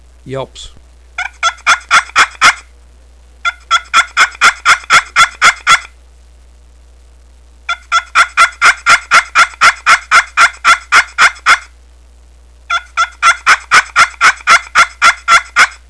perfpushpinyelps16.wav